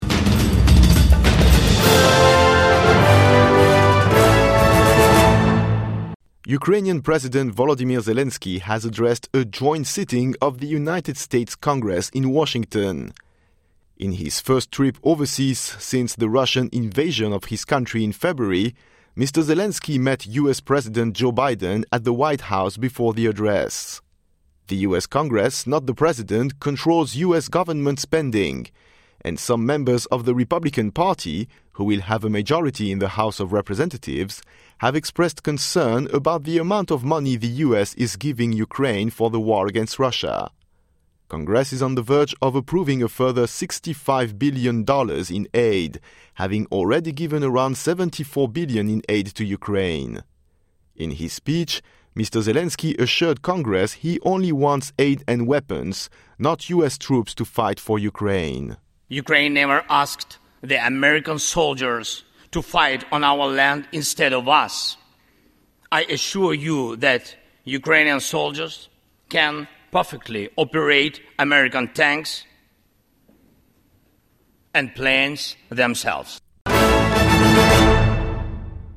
Ukrainian President Volodymyr Zelenskyy addresses US Congress.